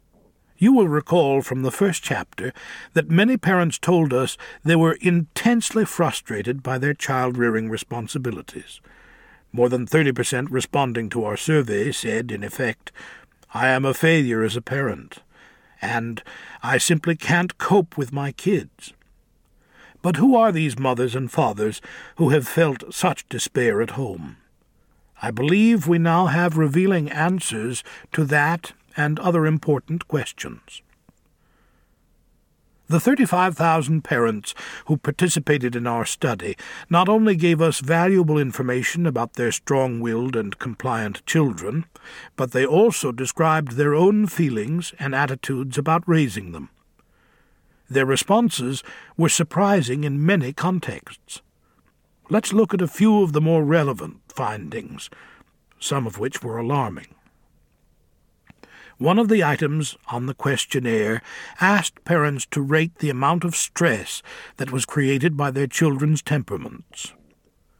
Parenting Isn’t for Cowards Audiobook
7.3 Hrs. – Unabridged